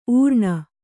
♪ ūrṇa